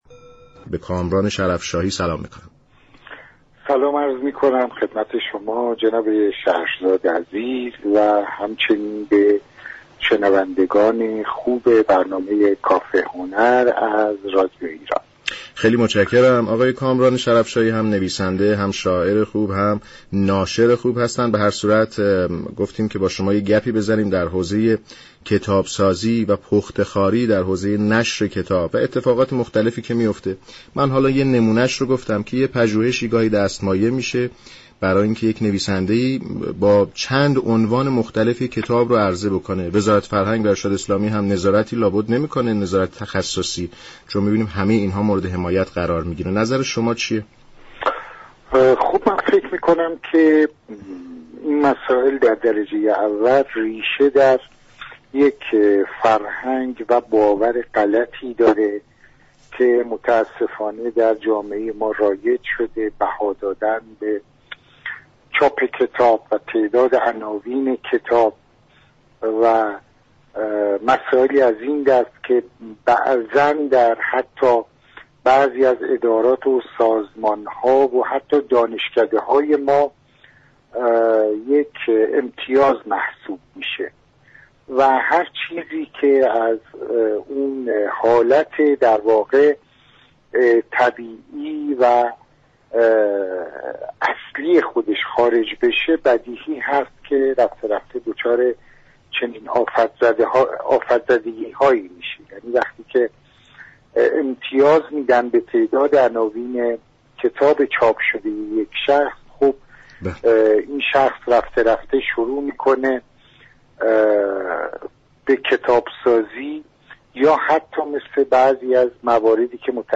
در گفت و گو با رادیو ایران گفت